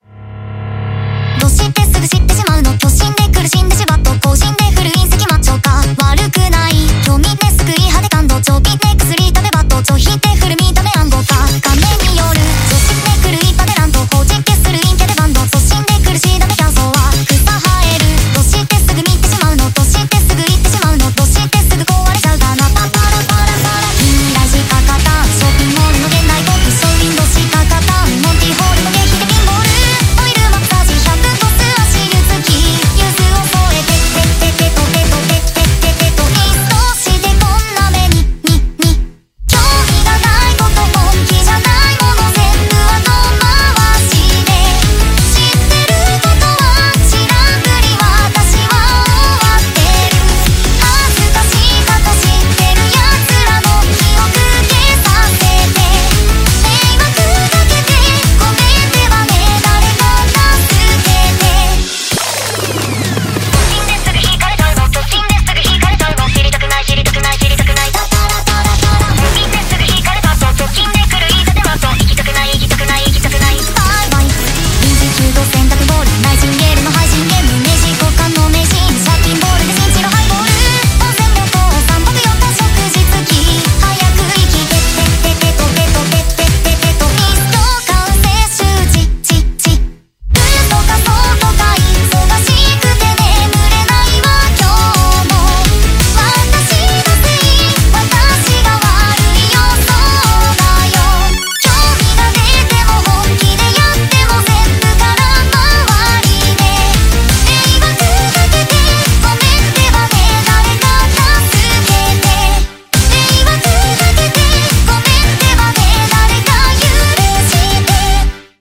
BPM170
Audio QualityPerfect (High Quality)
Song type: Vocaloid